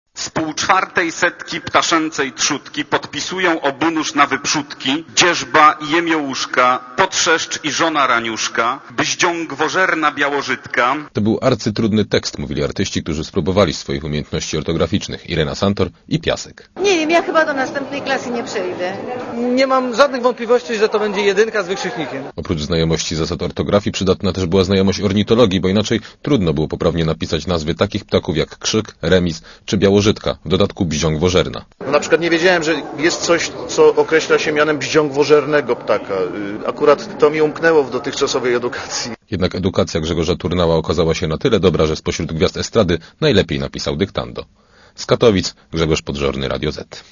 W katowickim "Spodku" ok. 6 tys. osób wzięło udział w 11. Ogólnopolskim Konkursie Ortograficznym.